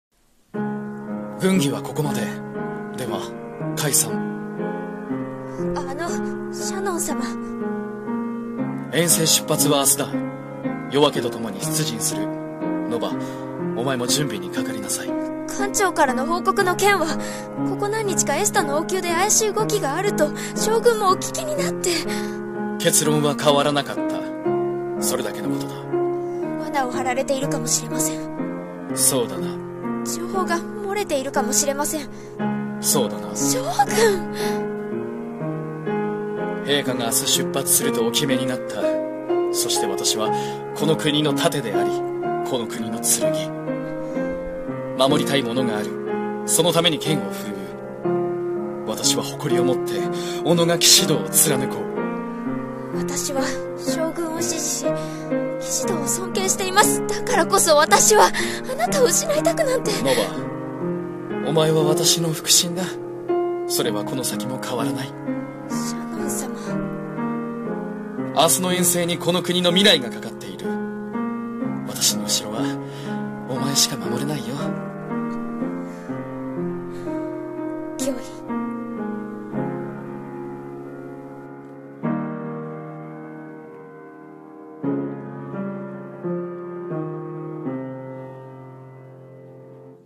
【声劇台本】守る剣となりましょう